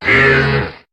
Cri de Crapustule dans Pokémon HOME.